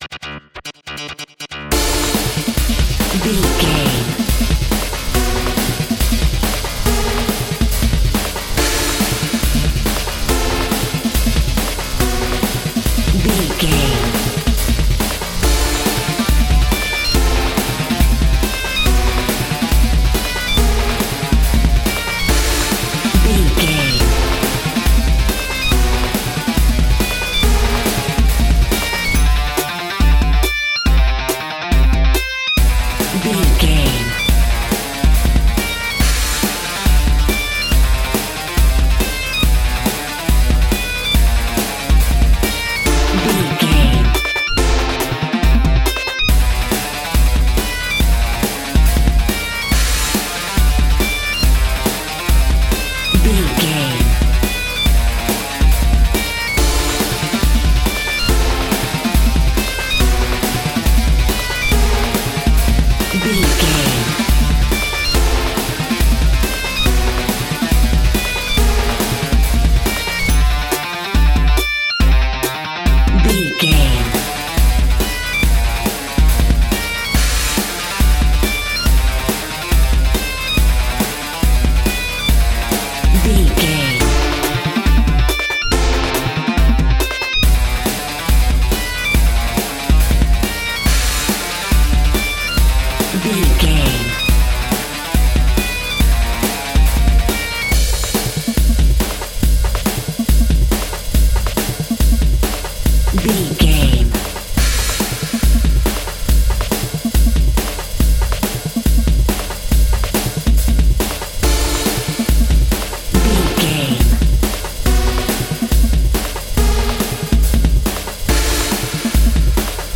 Fast paced
Aeolian/Minor
Fast
frantic
aggressive
driving
drums
synthesiser
drum machine
electronic
synth lead
synth bass